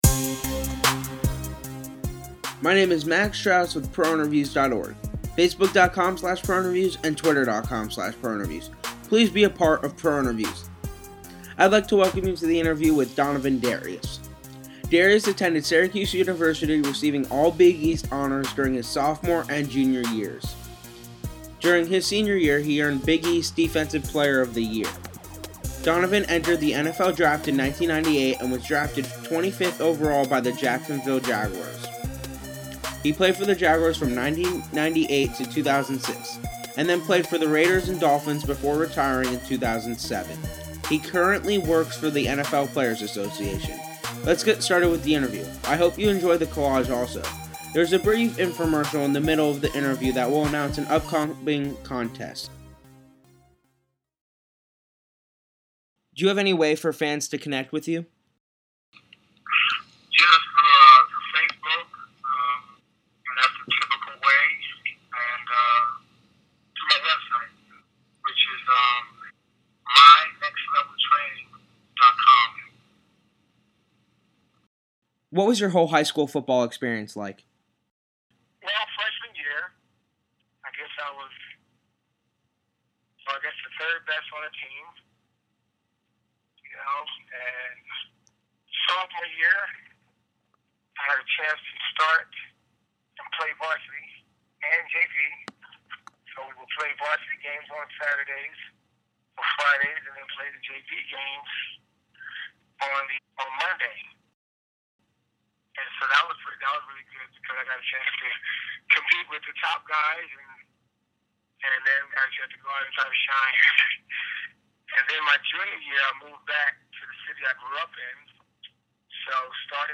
Check out our interview that was conducted after the 2010 NFL season was over.
interview-with-donovin-darius.mp3